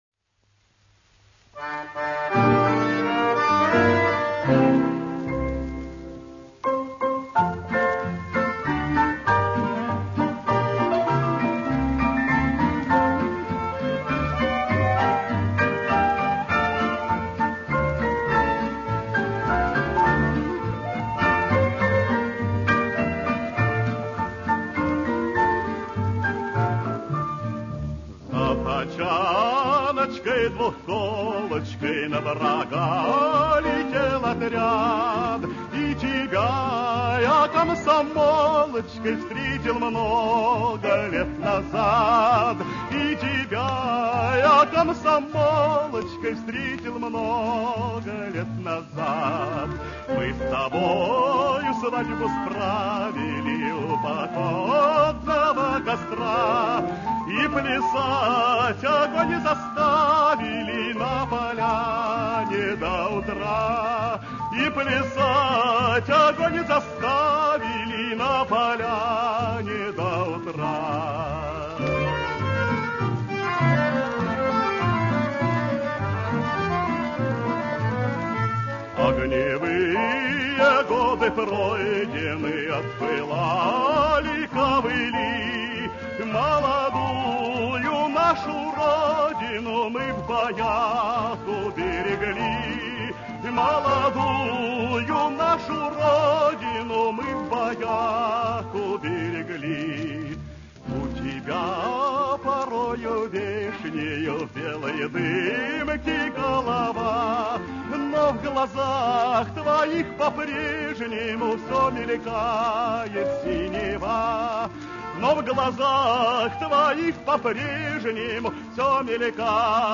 Ещё одна замечательная лирико-патриотическая песня.